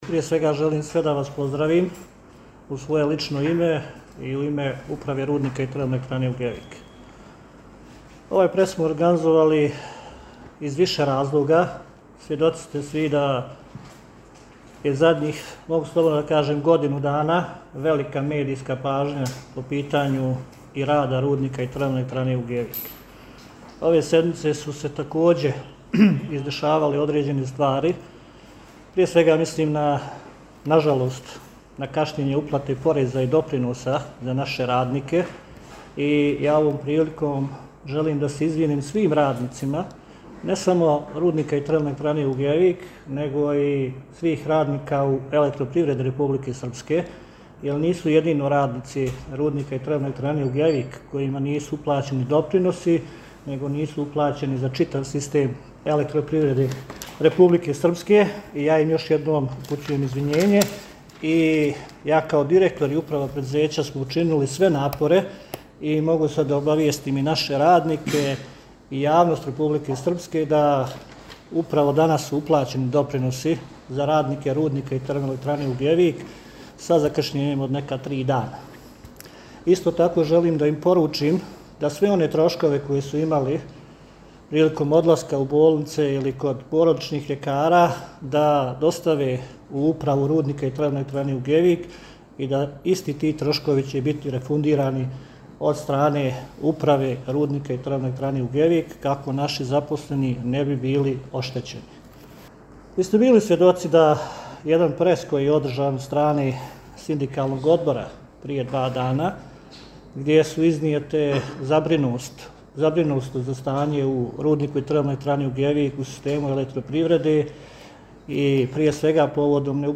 Nakon tri dana kašnjenja radnicima RiTE Ugljevik uplaćeni samodoprinosi istaknuto na konferenciji za medije koja je održana u RiTE Ugljevik, a na kojoj je bilo govora i o ostalim problemima sa kojima se ovo preduzeće susreće u poslednje vreme.